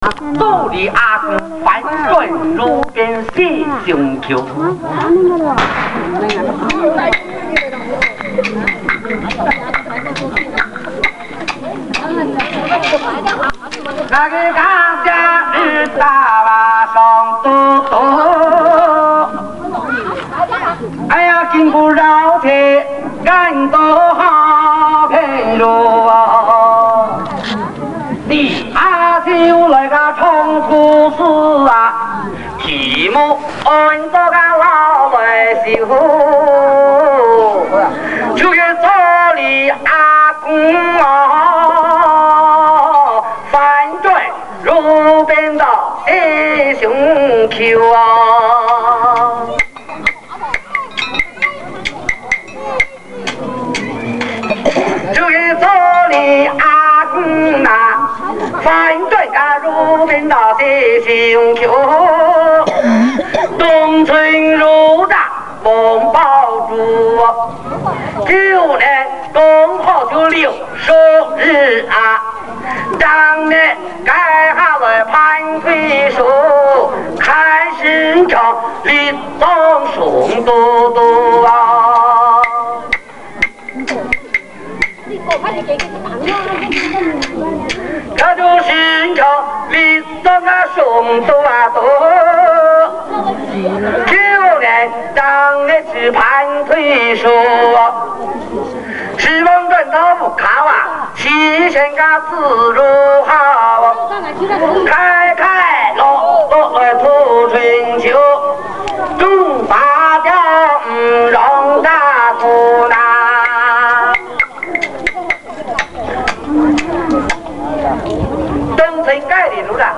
（现场版） - 客家传统歌曲